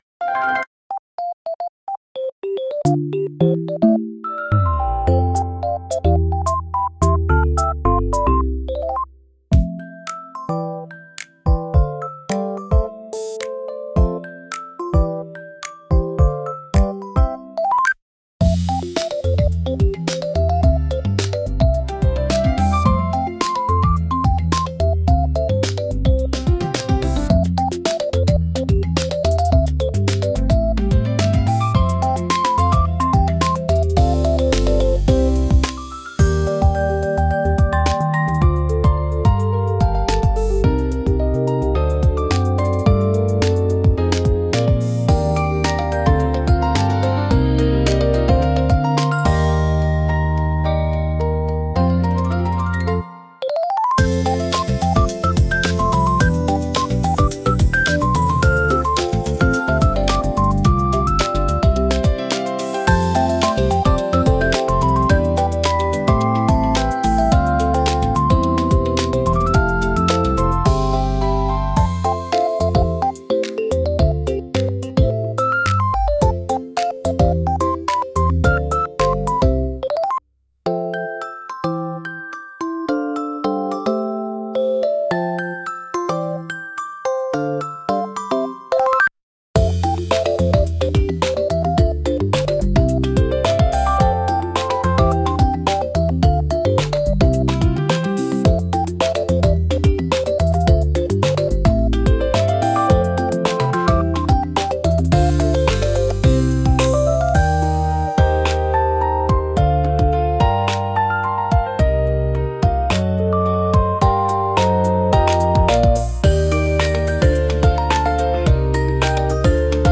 ゆめかわピコピコBGM